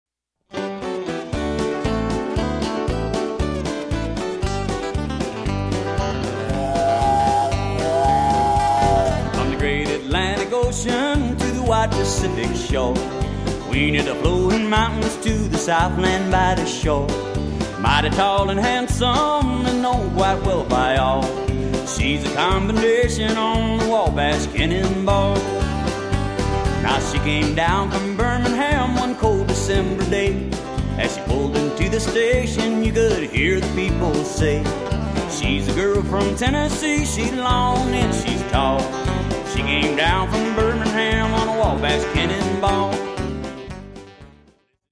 --cowboy music